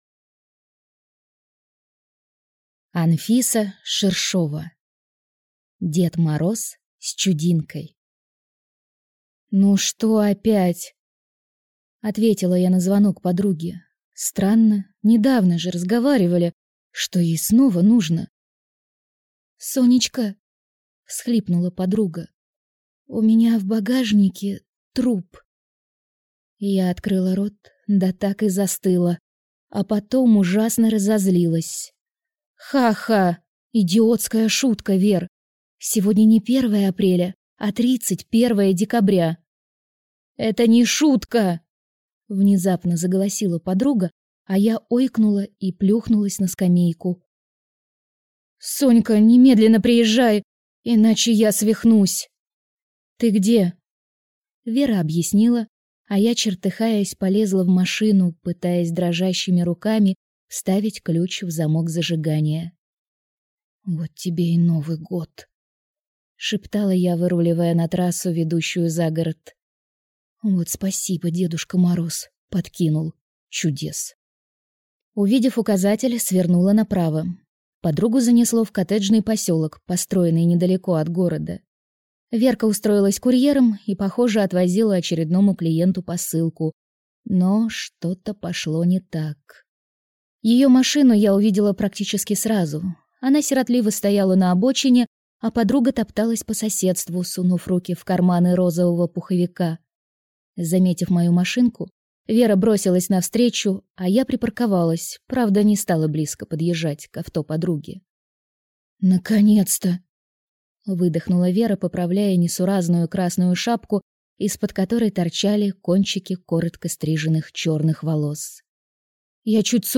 Аудиокнига Дед Мороз с чудинкой | Библиотека аудиокниг